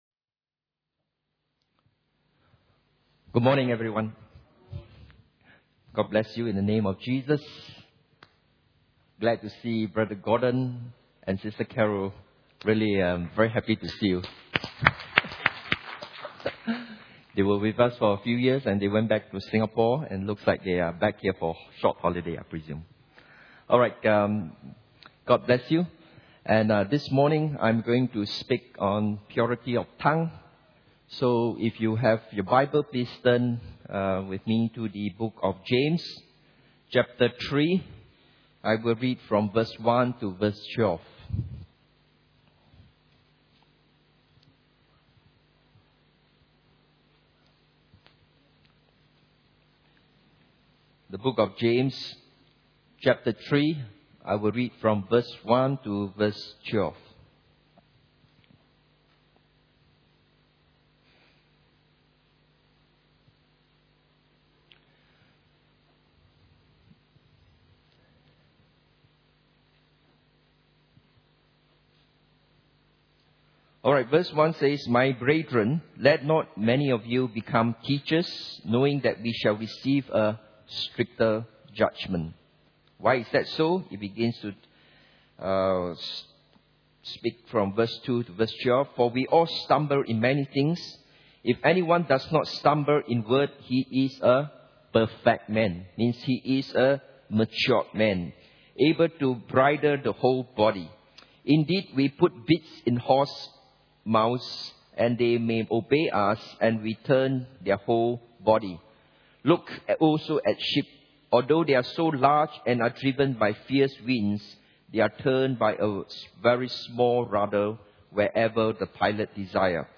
Holiness and Fear of God Service Type: Sunday Morning « Holiness and Fear of God pt 9